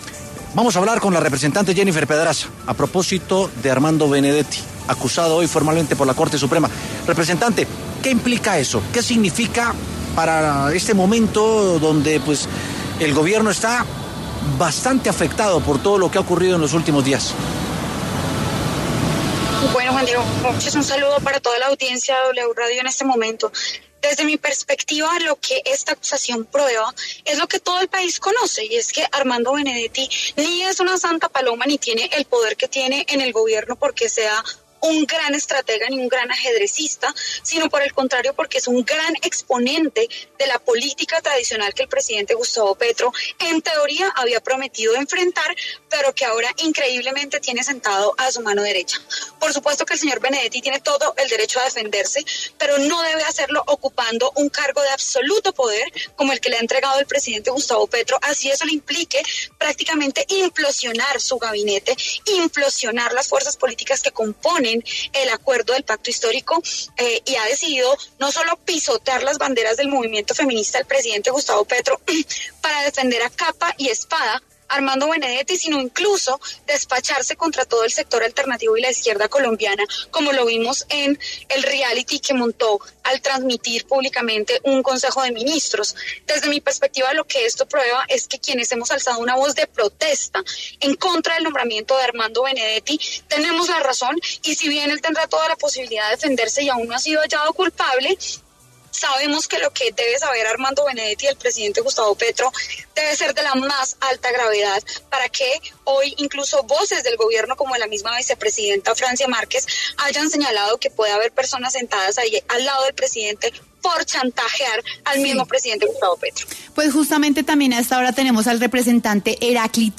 Por este motivo, en los micrófonos de W Sin Carreta habló la representante de Dignidad y Compromiso, Jennifer Pedraza, y el representante del Pacto Histórico, Heráclito Landinez.